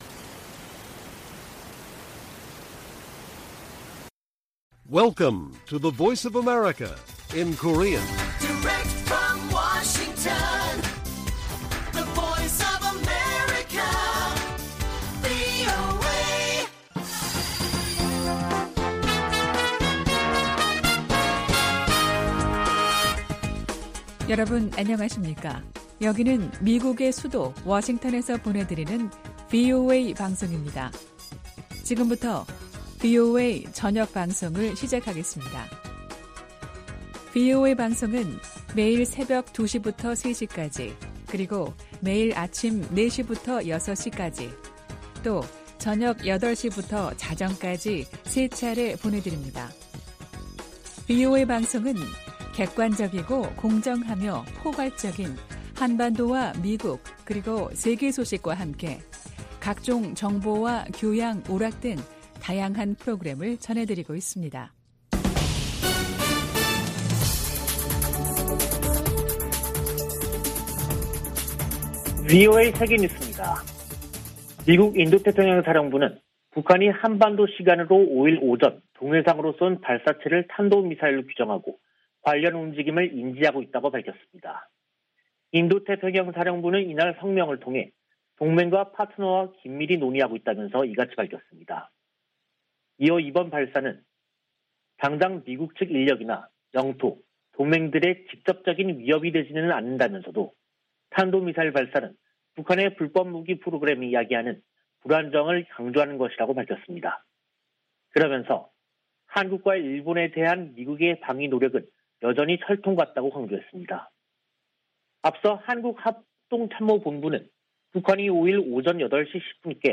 VOA 한국어 간판 뉴스 프로그램 '뉴스 투데이', 2022년 1월 5일 1부 방송입니다. 북한이 동해상으로 탄도미사일로 추정되는 발사체를 쐈습니다.